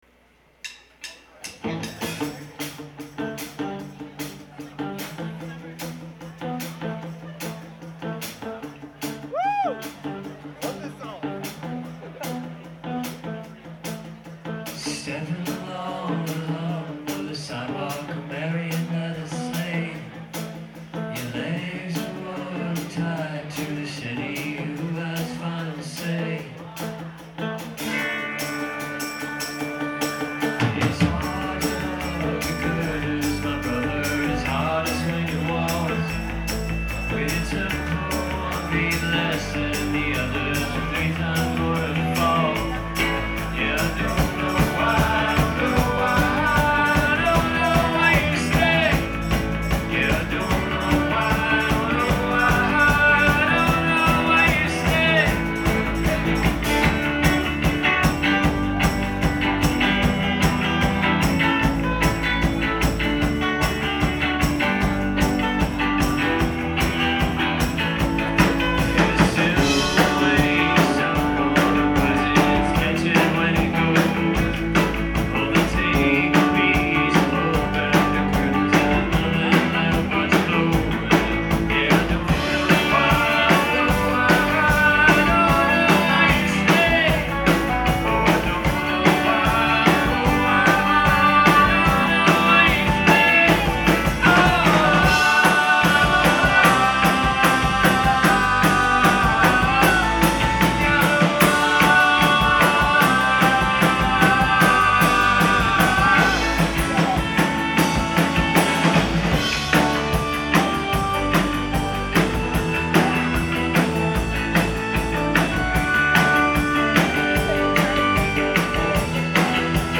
Live at The Paradise
in Boston, Mass.